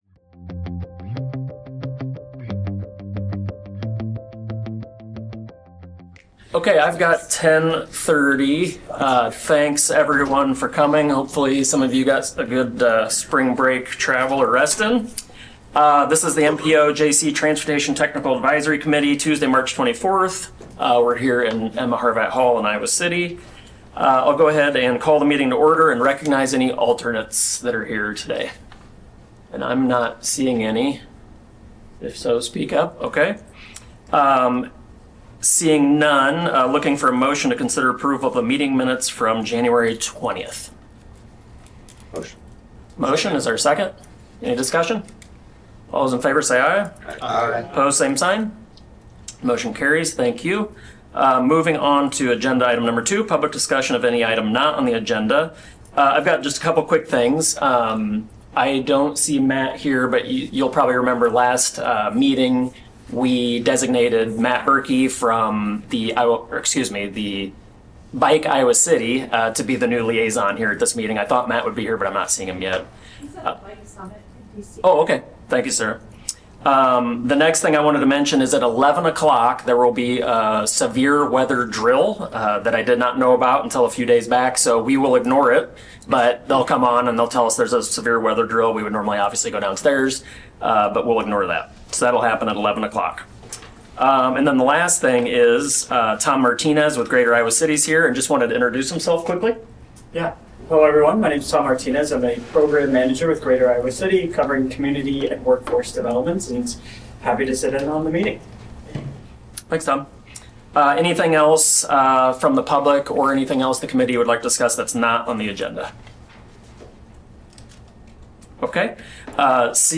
Metropolitan Planning Organization of Johnson County (MPOJC) Transportation Technical Advisory Committee Meeting of March 24, 20